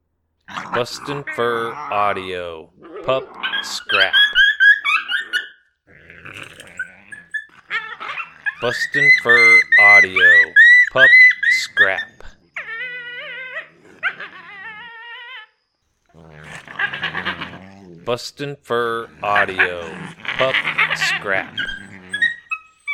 Smaller Coyote pups really getting nasty with each other to establish the pecking order.
• Product Code: pups and fights